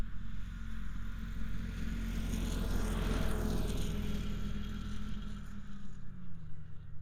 IC Snowmobile Description Form (PDF)
IC Subjective Noise Event Audio File (WAV)